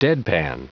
Prononciation du mot deadpan en anglais (fichier audio)
Prononciation du mot : deadpan